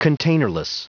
Prononciation du mot containerless en anglais (fichier audio)
Prononciation du mot : containerless